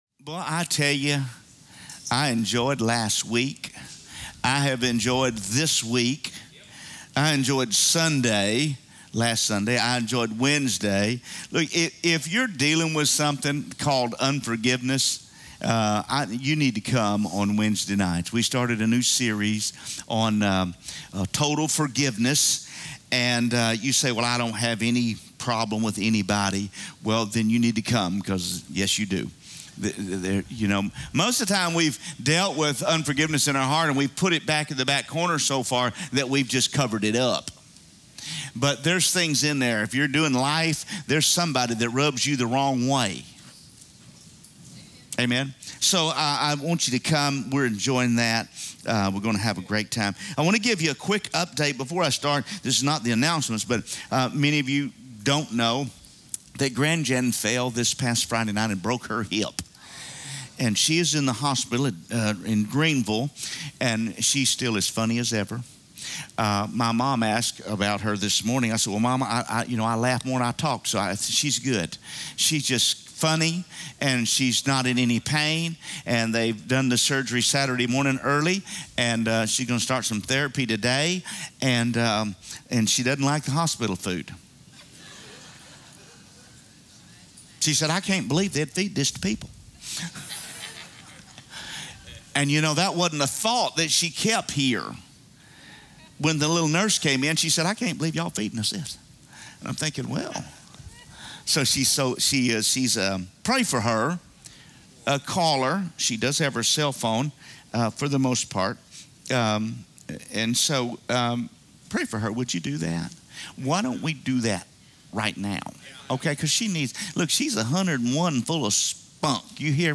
A message from the series "Sunday Message." What is the true duty of a Christian?